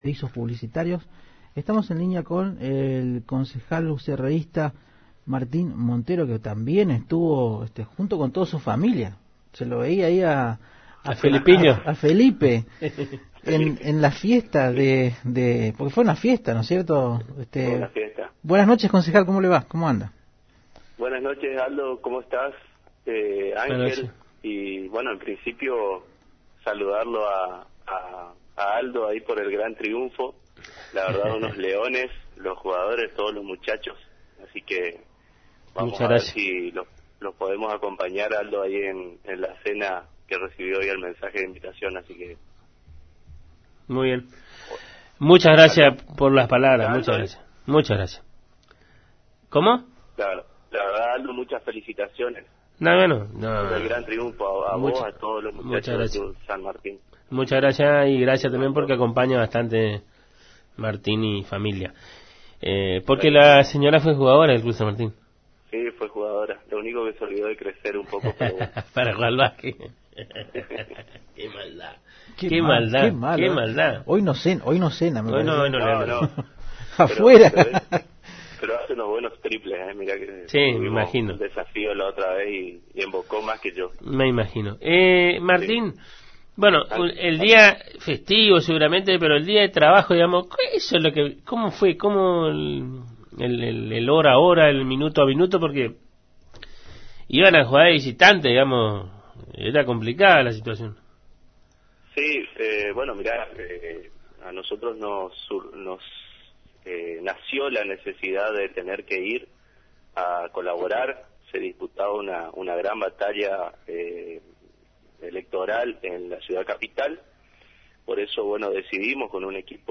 (Audio) El concejal ucerreísta Martín Montero dialogó con la AM 970 Radio Guarani y analizó la victoria electoral de ECO+Cambiemos que logró arrebatarle la intendencia al peronismo en Corrientes Capital. En este sentido, el edil también se refirió al resultado obtenido en lo que tiene que ver con las concejalías.